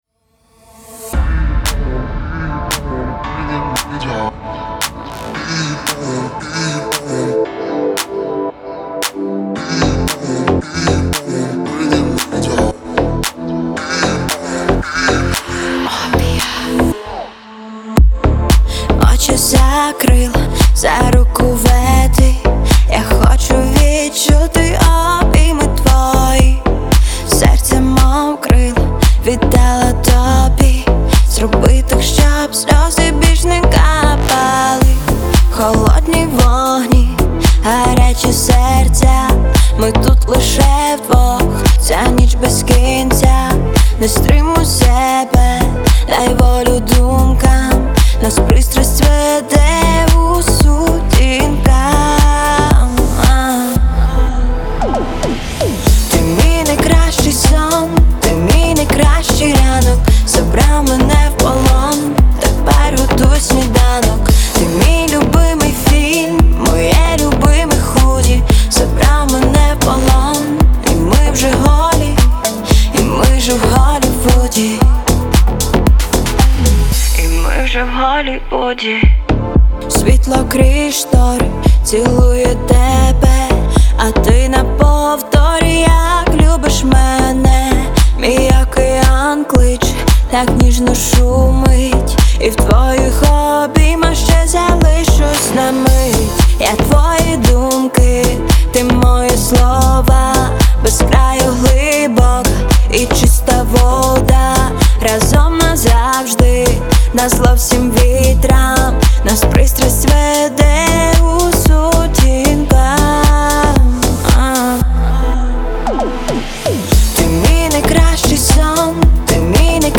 это яркий поп-трек с элементами электронной музыки